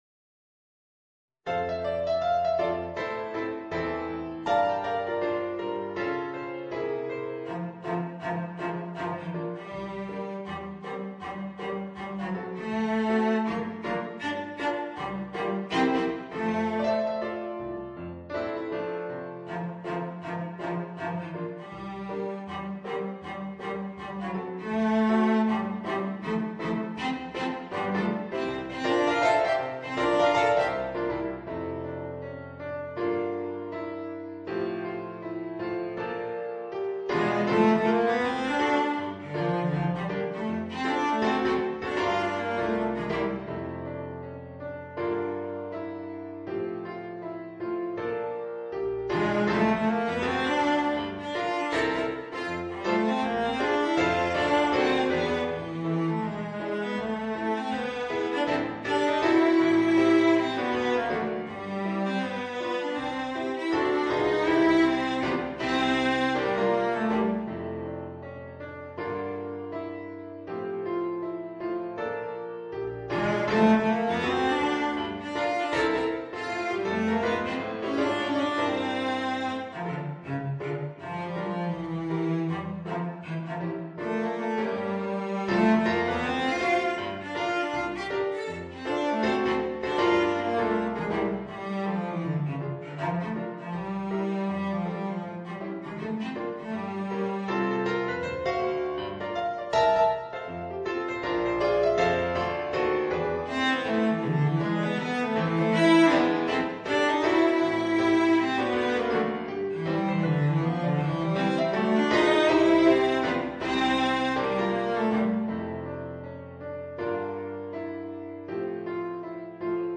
Voicing: Violoncello and Piano